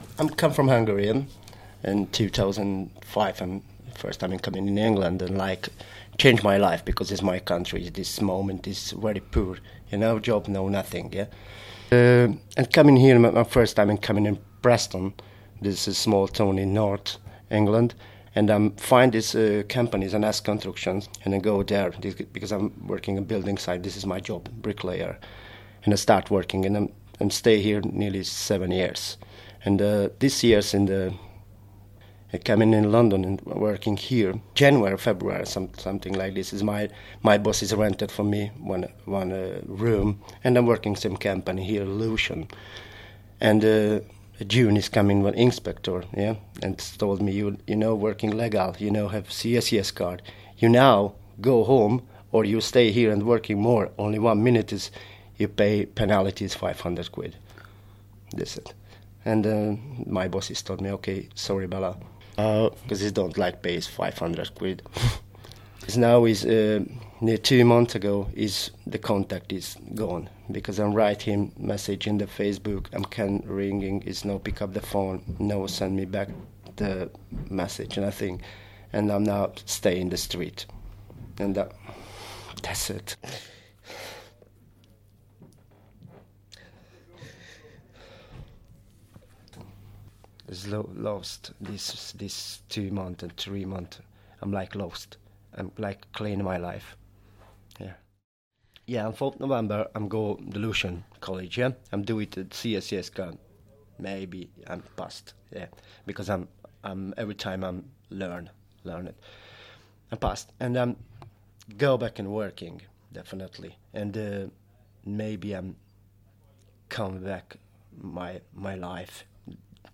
Here are a collection of people who are suffering in this tough economic time.